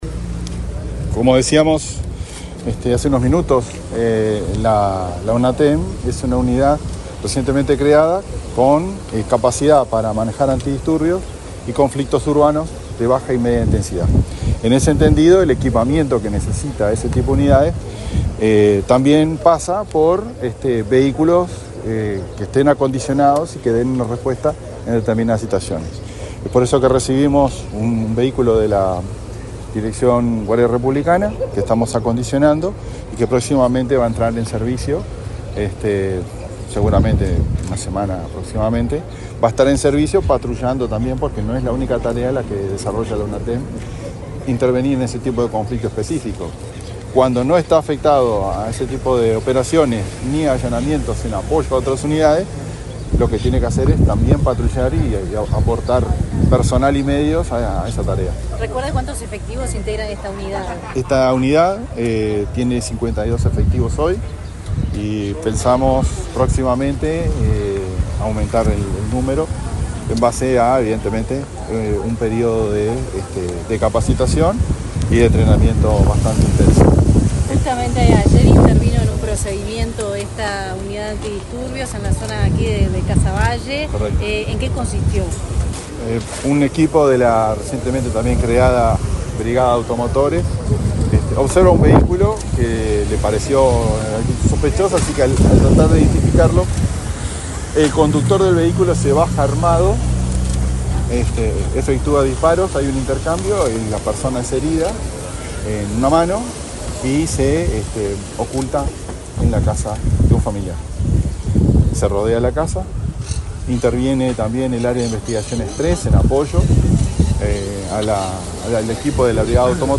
Declaraciones del jefe de Policía de Montevideo, Mario D'Elía
Este viernes 2, el jefe de Policía de Montevideo, Mario D'Elía, dialogó con la prensa en el barrio Casavalle, luego de la presentación de las obras de